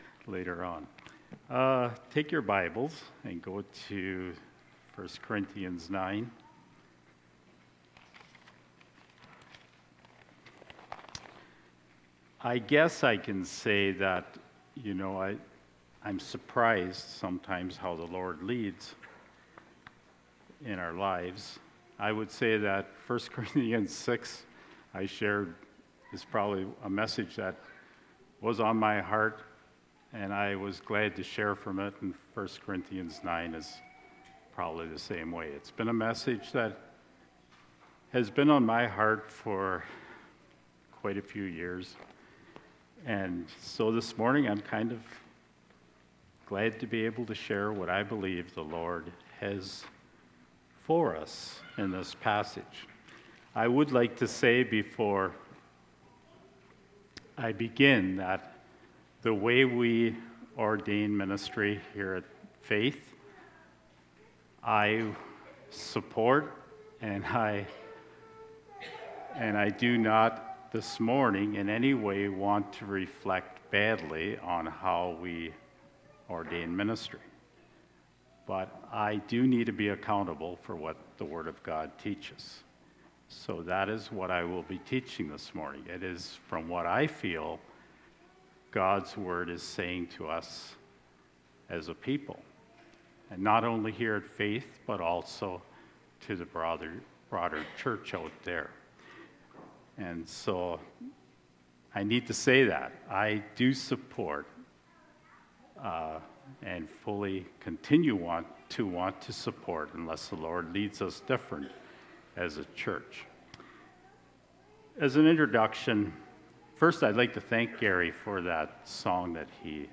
Sermons/Media - Faith Mennonite Church